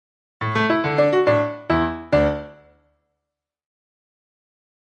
speaker_test.mp3